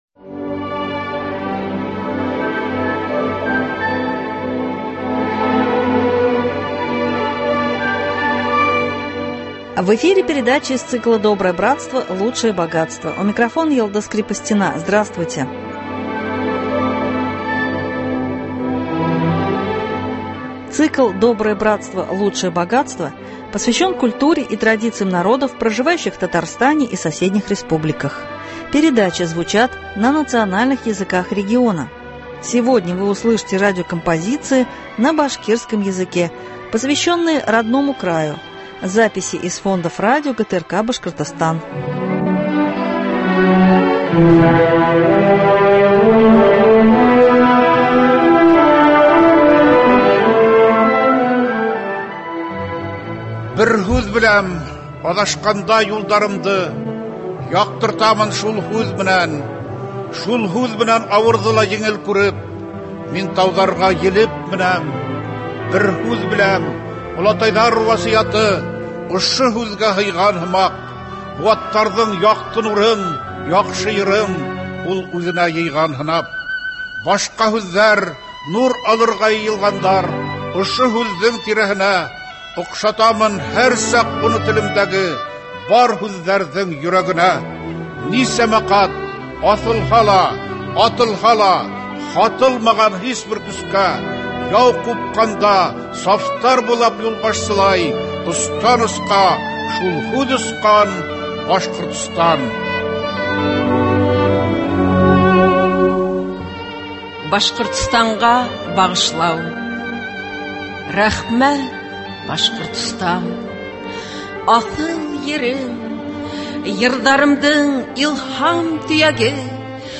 Цикл посвящен культуре и традициям народов, проживающих в Татарстане и соседних республиках, передачи звучат на национальных языках региона . Сегодня вы услышите радиокомпозиции на башкирском языке , посвященные родному краю. Записи из фондов радио ГТРК Башкортостан.